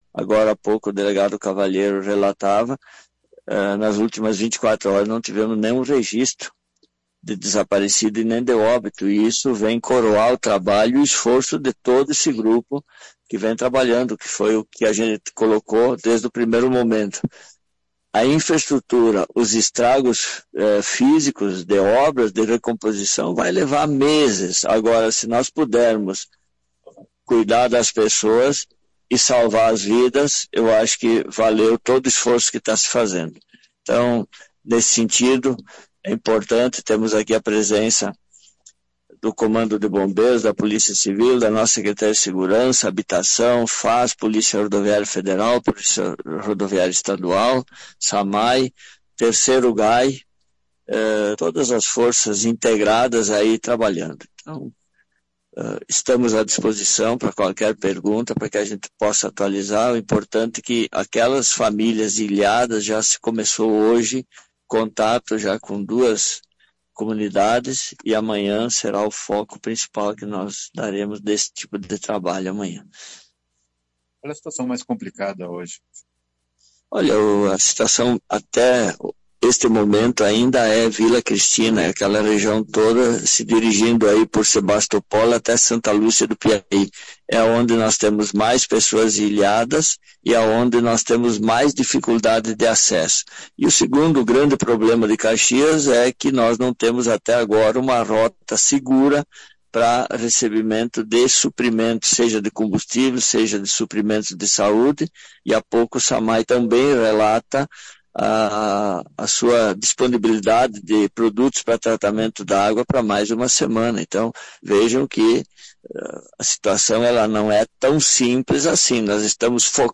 AQUI E CONFIRA A DECLARAÇÃO COMPLETA).